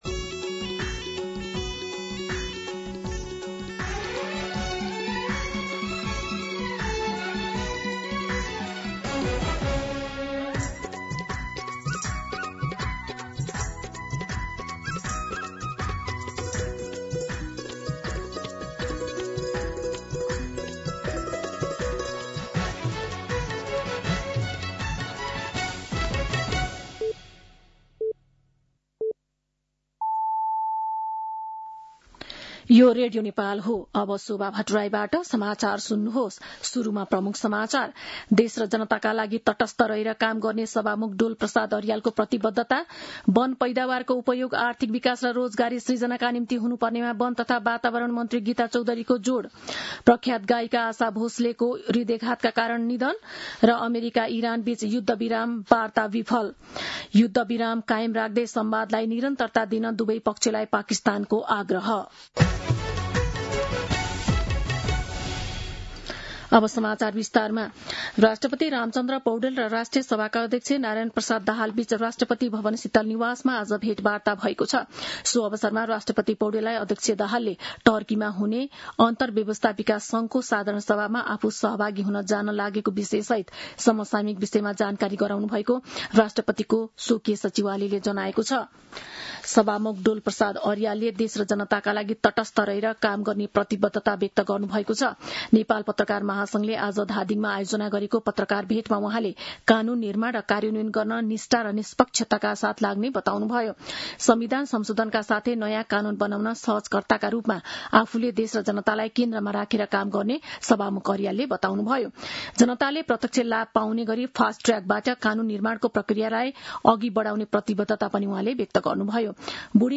दिउँसो ३ बजेको नेपाली समाचार : २९ चैत , २०८२